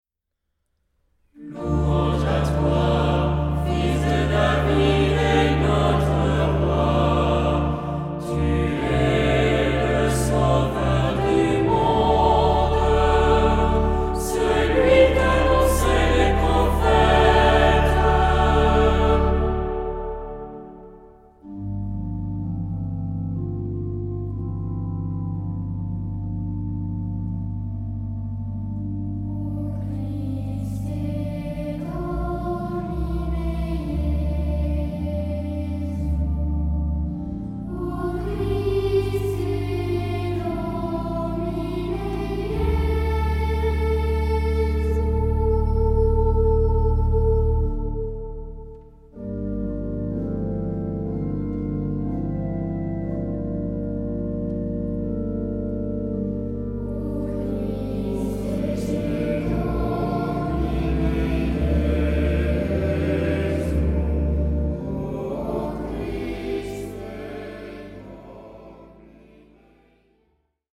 Genre-Style-Form: troparium ; Psalmody ; Sacred
Mood of the piece: collected
Type of Choir: SATB  (4 mixed voices )
Instruments: Organ (1)
Tonality: D tonal center ; G minor